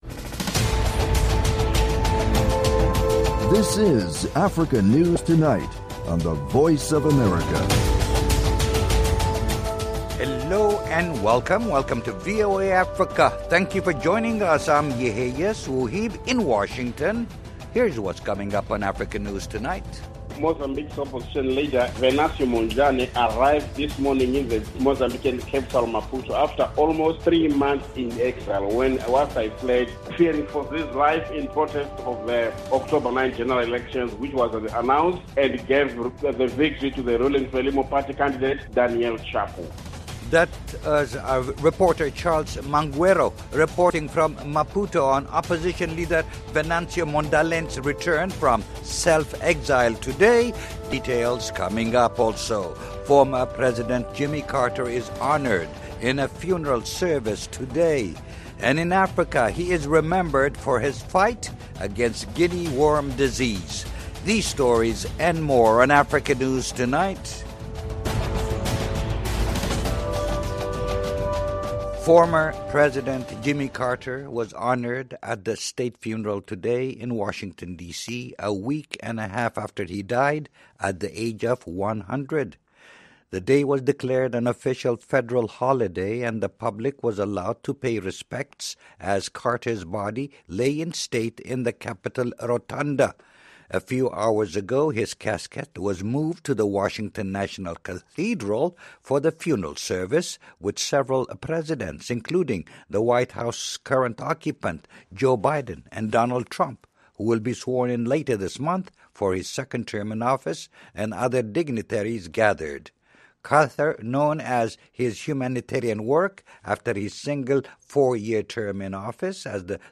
1 Africa News Tonight - January 09, 2025 24:55 Play Pause 1h ago 24:55 Play Pause Riproduci in seguito Riproduci in seguito Liste Like Like aggiunto 24:55 Africa News Tonight is a lively news magazine show featuring VOA correspondent reports, interviews with African officials, opposition leaders, NGOs and human rights activists.